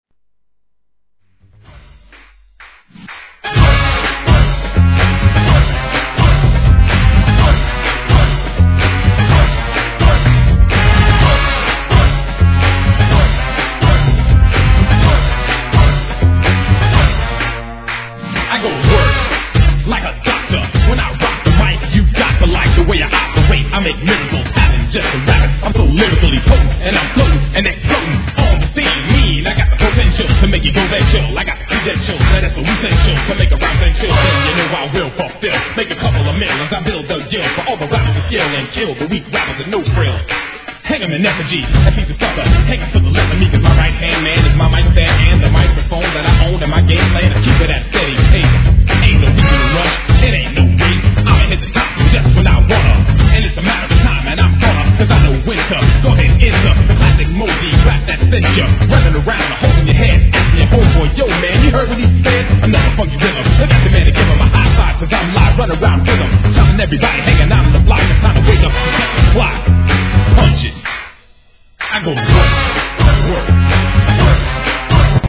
HIP HOP/R&B
博士号を３つも取得するRAP界きってのインテリ・ラッパー！